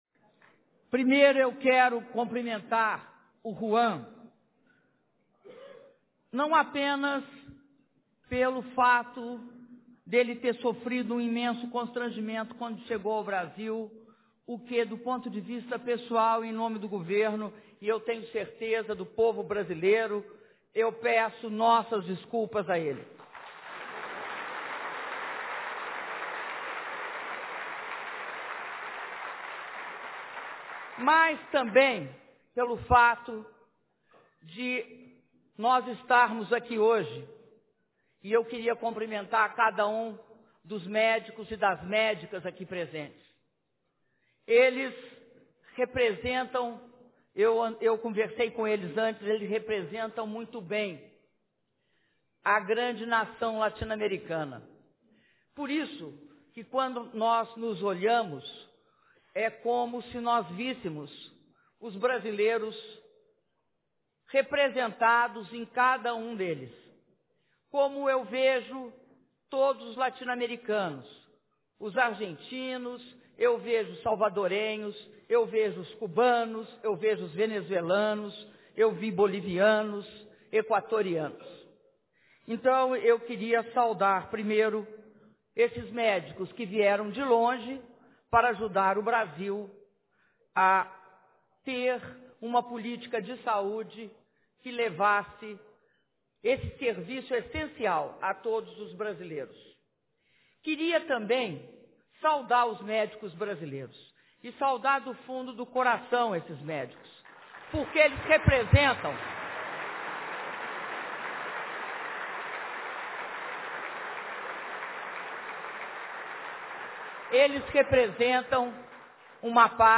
Áudio do discurso da Presidenta da República, Dilma Rousseff, na cerimônia de sanção da Lei que institui o Programa Mais Médicos - Brasília/DF (38min02s)
Discurso da Presidenta Dilma Rousseff na cerimônia de sanção da Lei que institui o Programa Mais Médicos - Brasília/DF